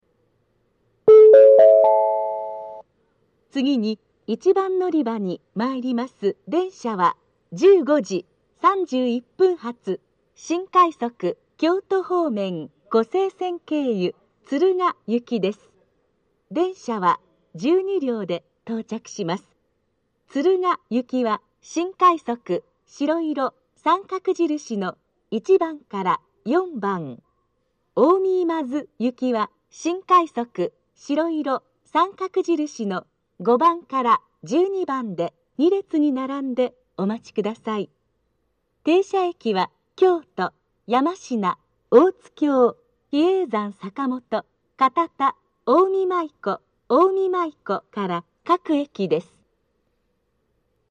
東海道京阪神型
（女性）
到着予告放送 15：31発 新快速 湖西線経由 敦賀行き 12両編成（前4両敦賀行き,後8両近江今津行き）の自動放送です。